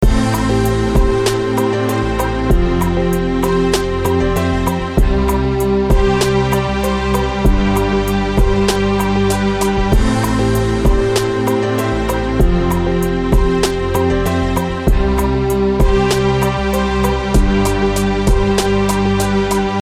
少了一个Grooveloop的节拍
Tag: 97 bpm Hip Hop Loops Groove Loops 3.33 MB wav Key : Unknown